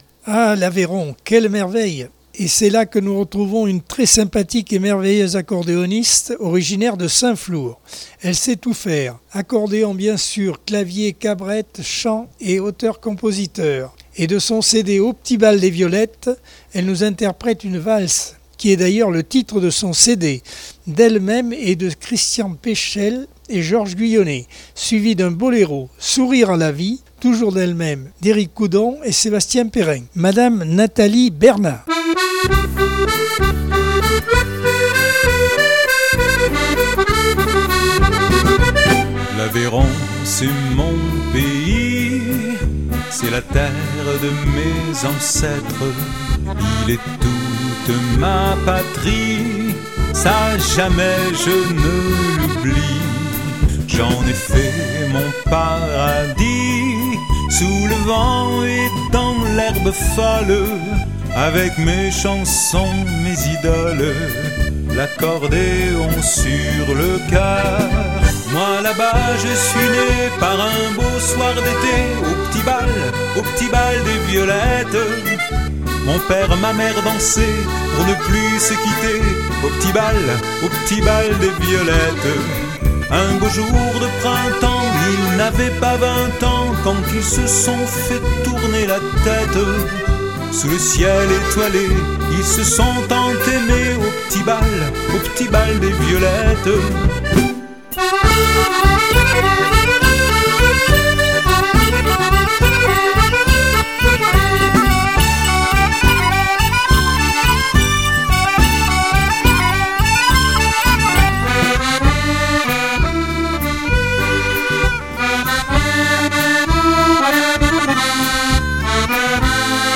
Accordeon 2022 sem 32 bloc 3 - Radio ACX